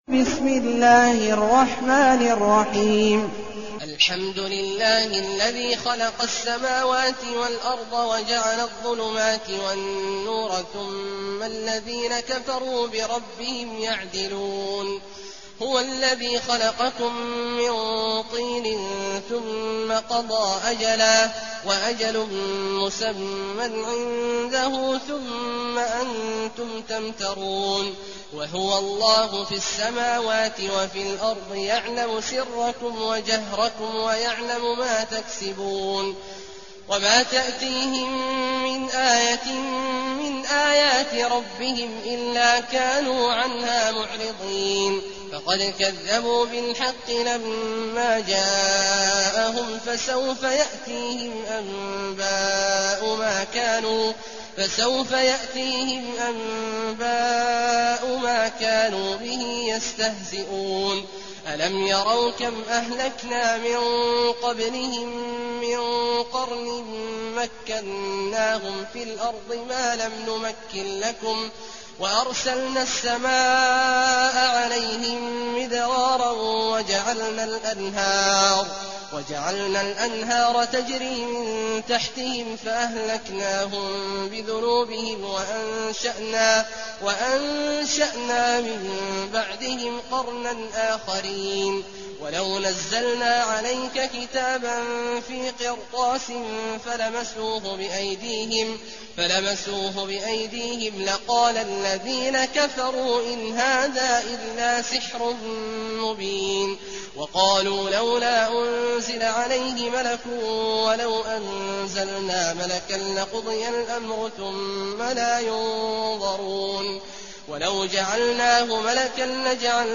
المكان: المسجد الحرام الشيخ: عبد الله عواد الجهني عبد الله عواد الجهني الأنعام